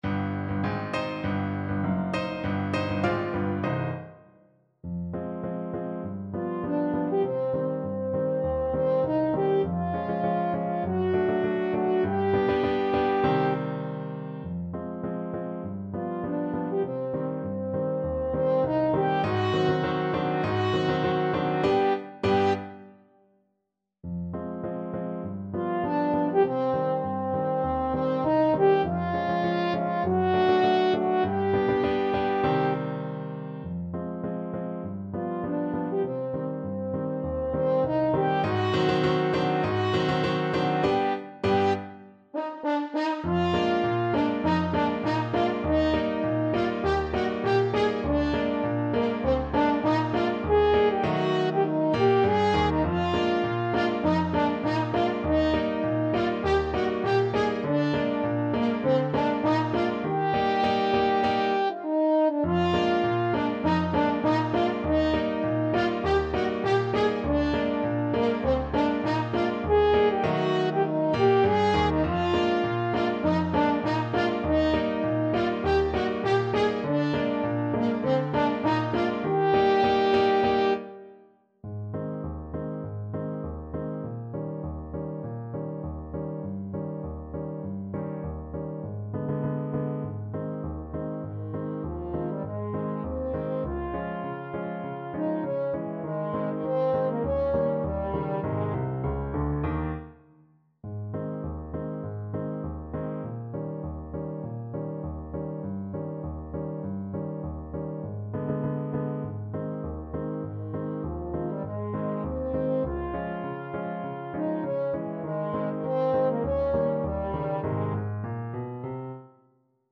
Play (or use space bar on your keyboard) Pause Music Playalong - Player 1 Accompaniment reset tempo print settings full screen
Quick March = c. 100
C minor (Sounding Pitch) (View more C minor Music for Trumpet-French Horn Duet )
Classical (View more Classical Trumpet-French Horn Duet Music)